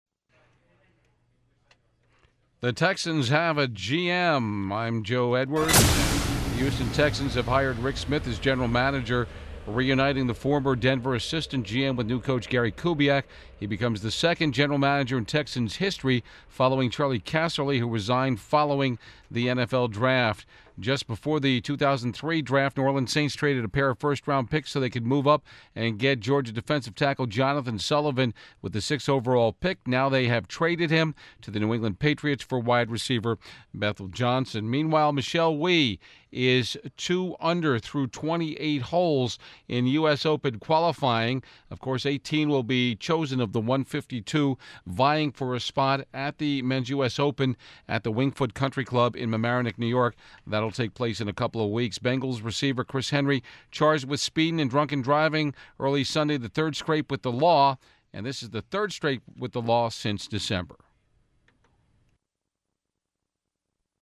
THE SOUNDS OF SPORTS
Sixty-second sports updates in AM, Midday and PM Drives.